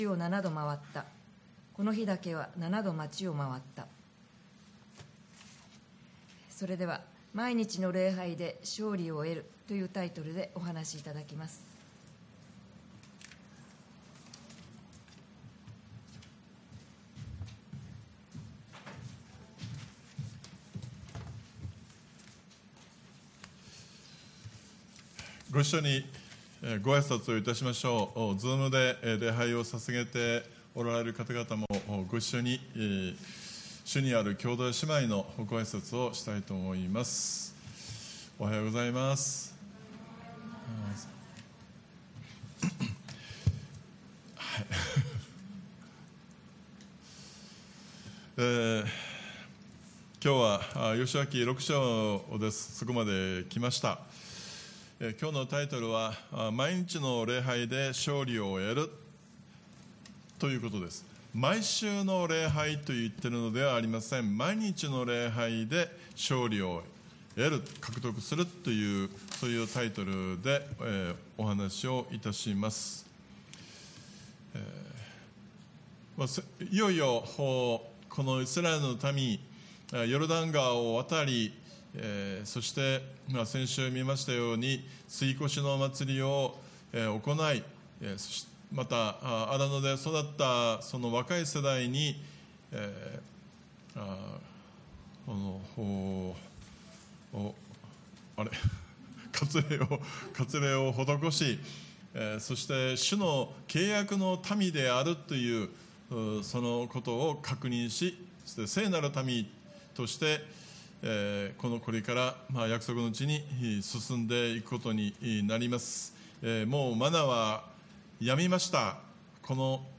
礼拝メッセージ(説教)
主日礼拝